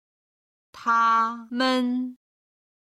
※軽声の音源がないので、一声を使っています。その為、音源のように音を伸ばさなくてもOKです。